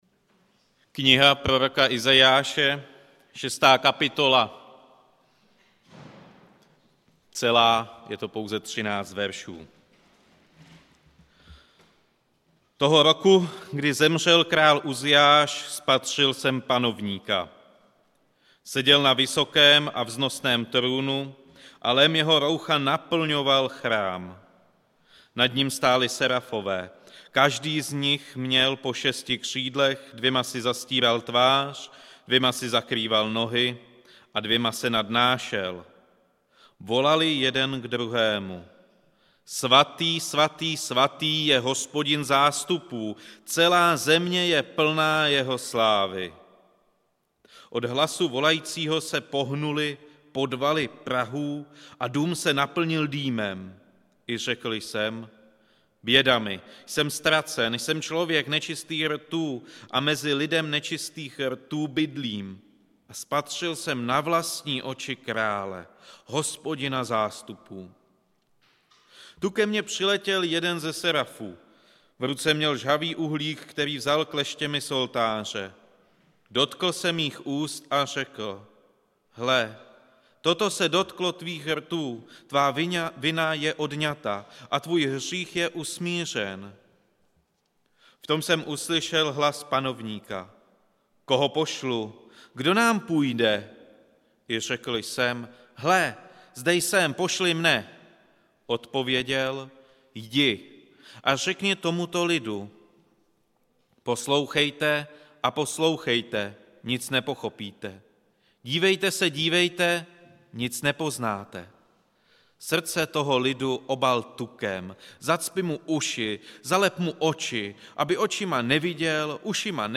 Kázání
Událost: Kázání